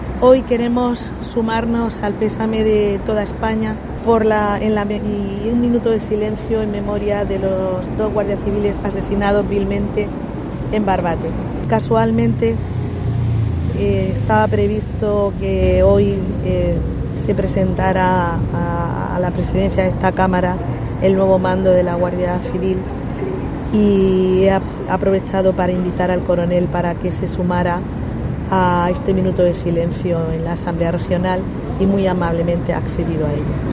Minuto de silencio a las puertas de la Asamblea en homenaje a los dos guardia civiles fallecidos